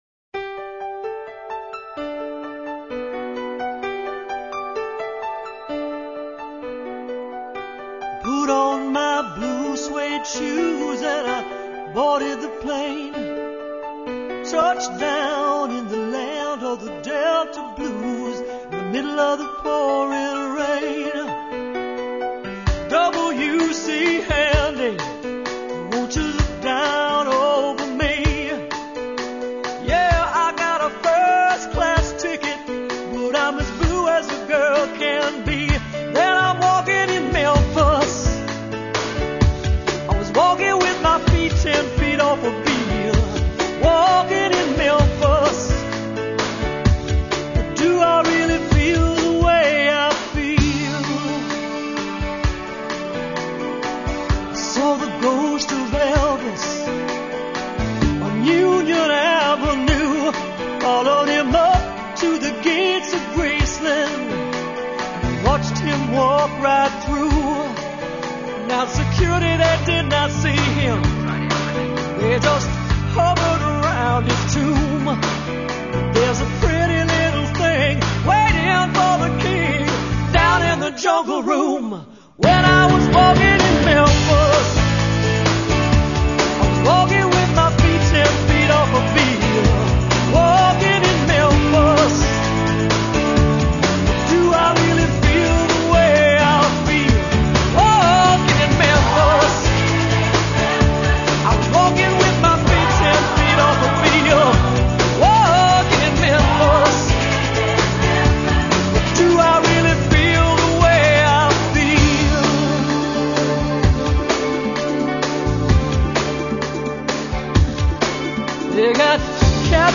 22kHz Mono